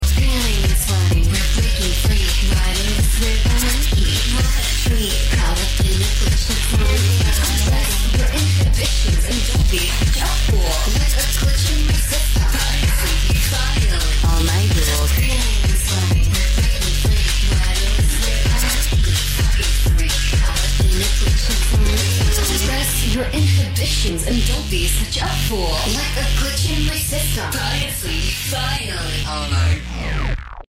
2B Song Panting And Sound Effects Free Download